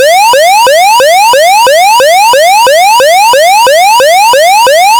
• (1) 基本波形は、矩形波とすること。
サンプル音2 （周波数 500～1,000Hz／周期 毎秒3回）